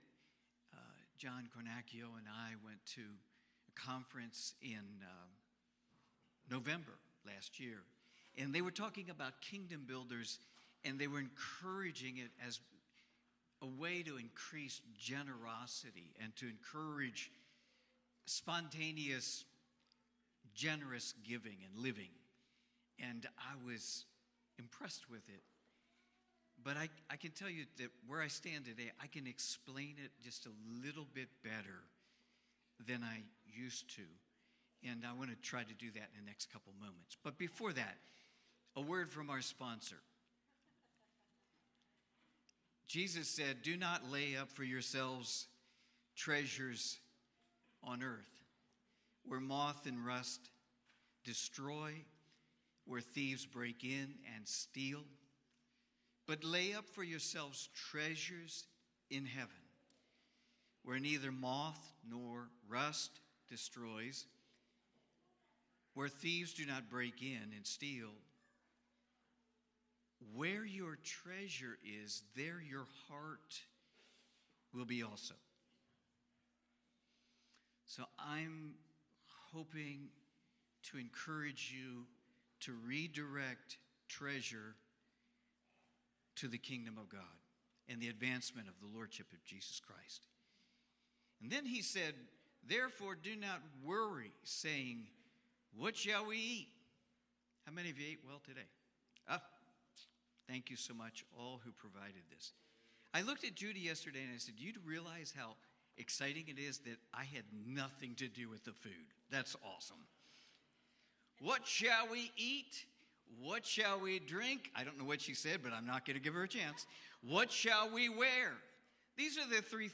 Sunday Morning 2025-04-27